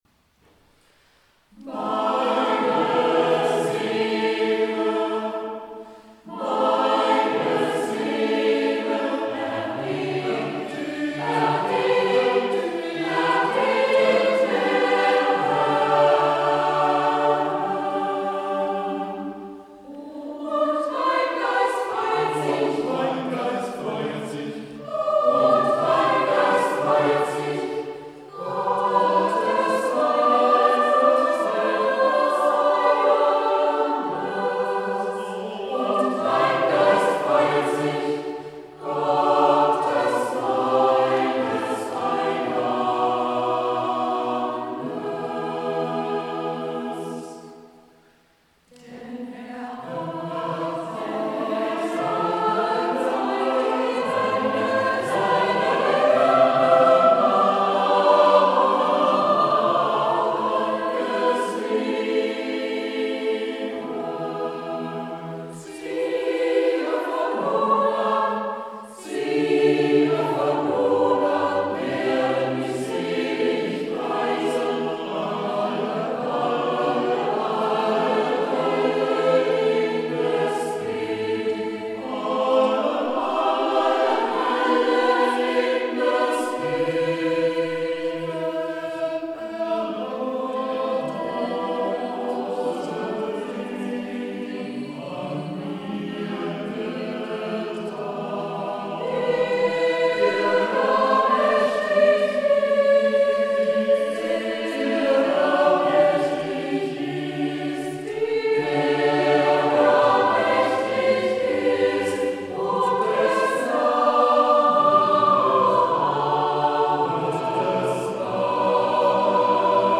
Dieser Oratorienchor spannt ein musikalisches Profil von Barock bis in die beginnende Moderne auf, auch anspruchsvolle A-cappella Konzerte werden zu Gehör gebracht.
Hörbeispiele der Kantorei